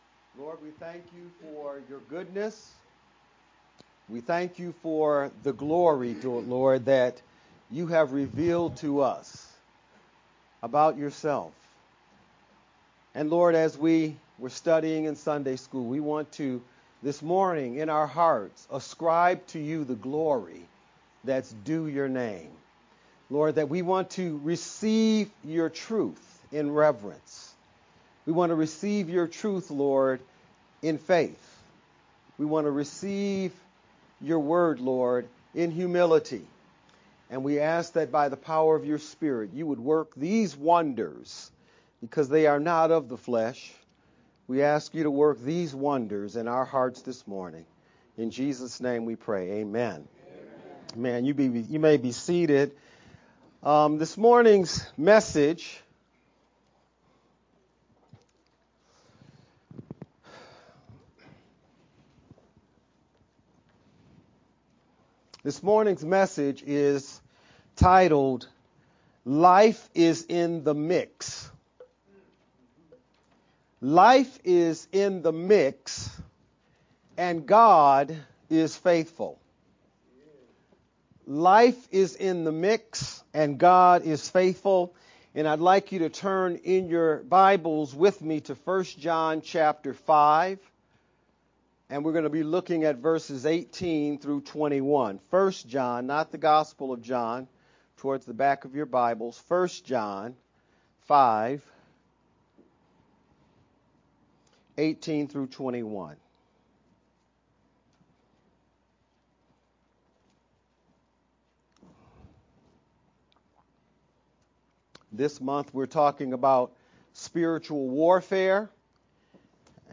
VBCC-Sermon-only-edited-7-13_Converted-CD.mp3